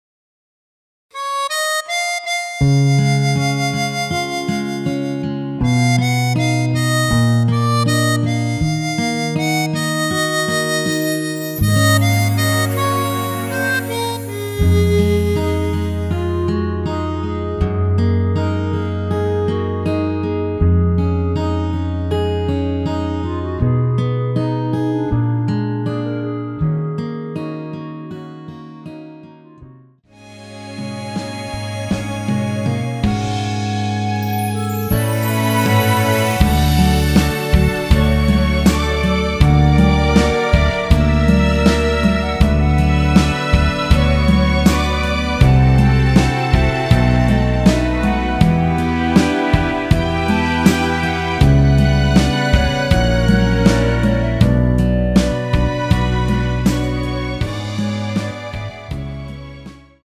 원키 멜로디 포함된 MR입니다.(미리듣기 확인)
Db
멜로디 MR이라고 합니다.
앞부분30초, 뒷부분30초씩 편집해서 올려 드리고 있습니다.
중간에 음이 끈어지고 다시 나오는 이유는